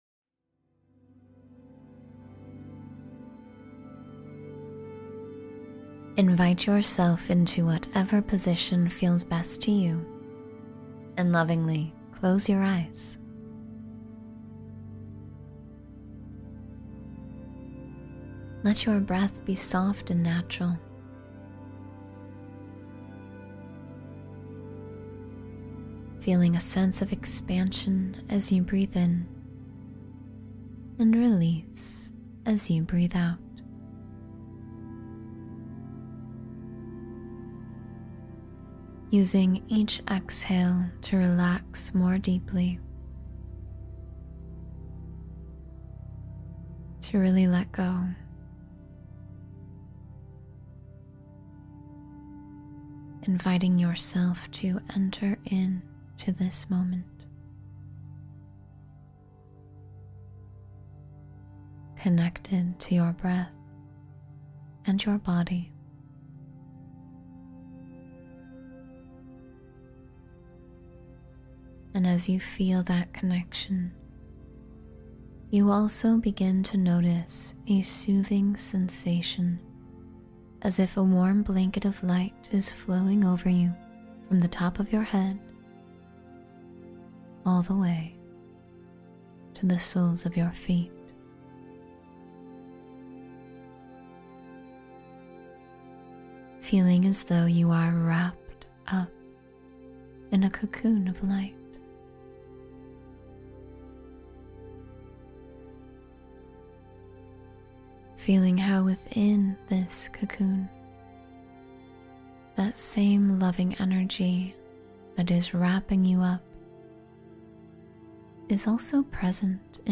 Connect to Source Energy Within — Guided Meditation for Spiritual Alignment